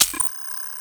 deploy.wav